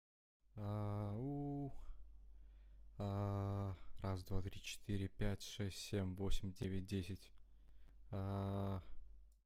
Заикает вывод голоса в таких приложениях как discord,telegram(гс) и так далее..help!(
Приобрёл m audio air 192 4 и микрофон к нему behringer b1. в настройках микшера в микрофоне стоит 48/24. в наушниках по usb(отдельные, не подключены к звуковой карте) тоже самое.
прикрепляю тестовую запись с телеграма